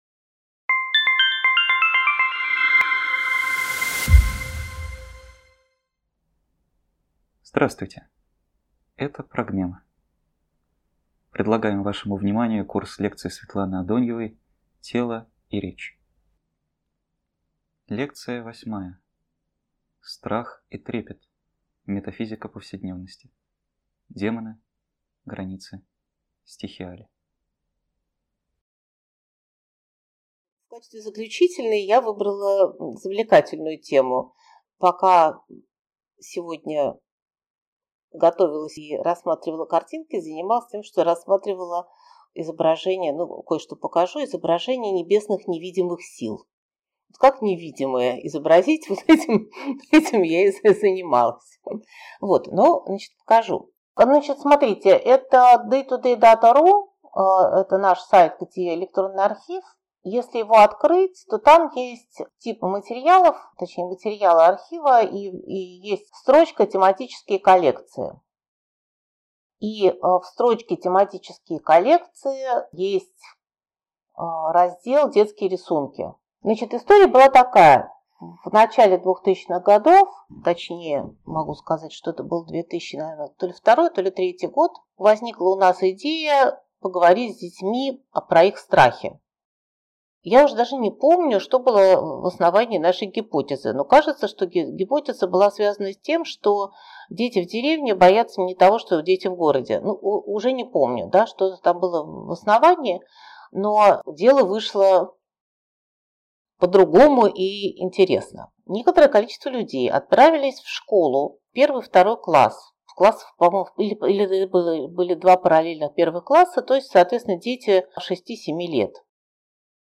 Тело и речь. Лекция 8.